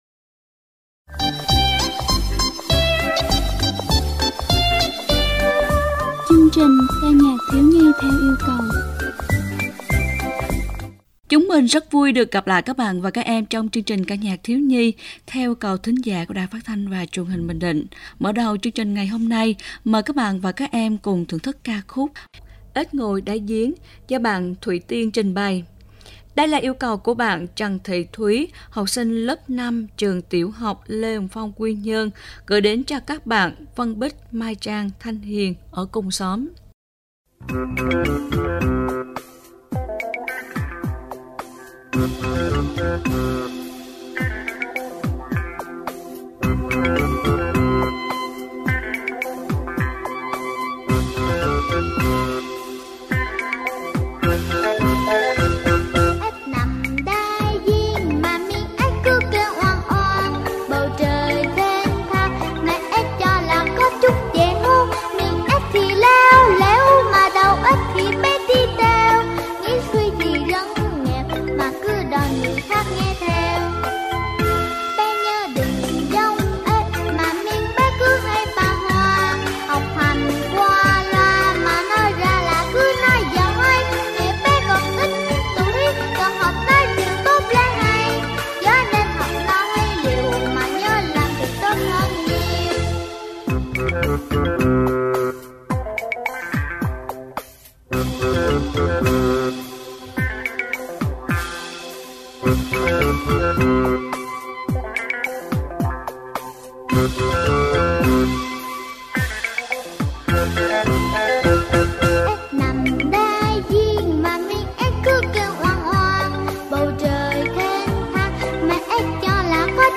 Ca Nhạc Thiếu Nhi
7-11-ca-nhac-thieu-nhi-yeu-cau.mp3